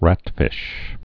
(rătfĭsh)